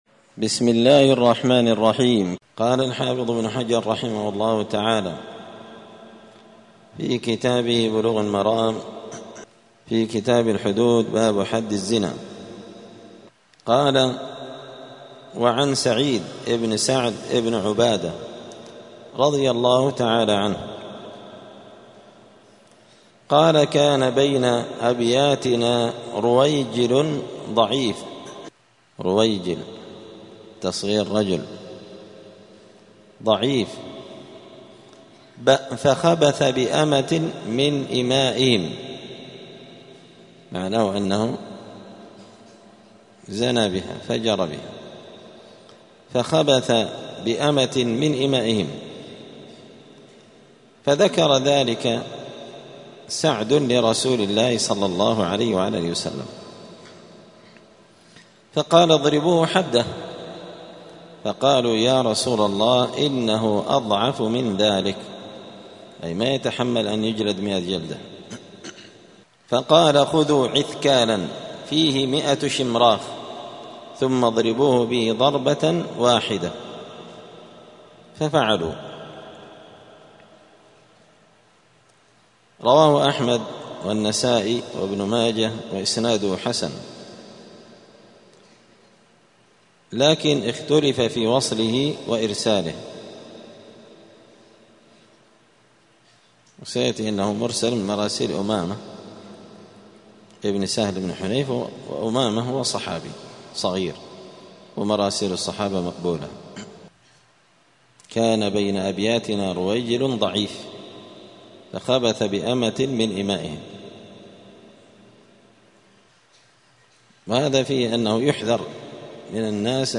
*الدرس العاشر (10) {باب إقامة حد الزنا على الضعيف}*